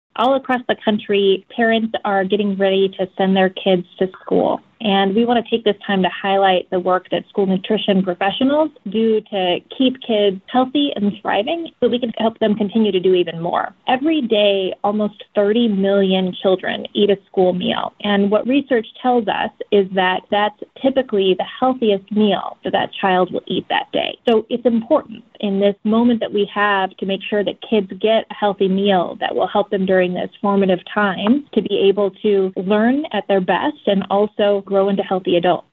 Audio with USDA Deputy Secretary Xochitl (So-CHEEL) Torres Small